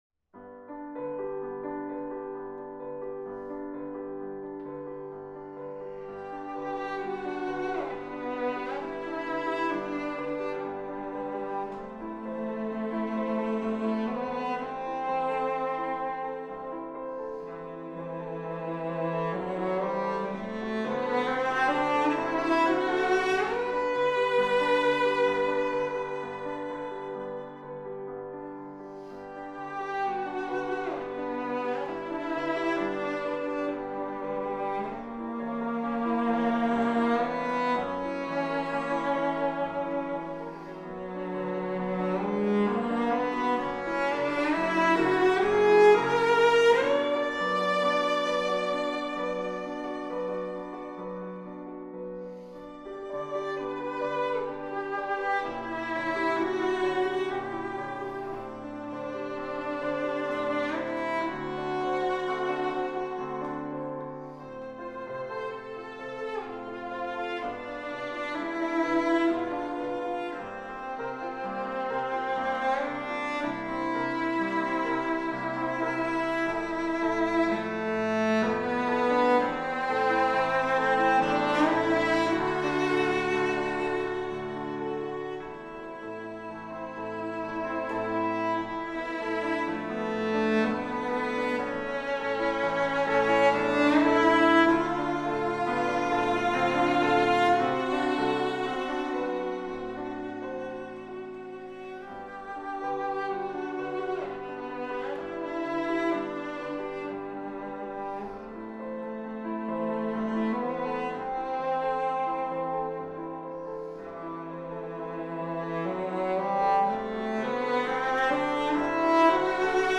cello
piano
Duo Cavatine performs Camille Saint-Saëns' "Le cygne"